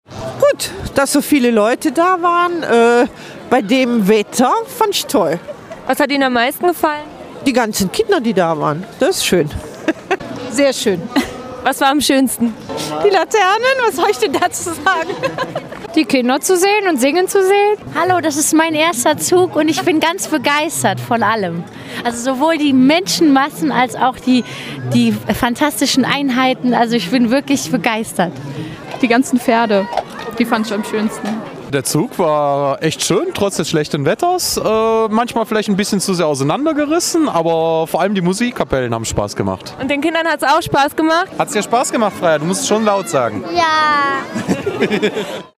Nur gestern spielte er nicht mit, als sich die Laternen-Karawane durch Eupen schob…es regnete. Dennoch war die Stimmung gut: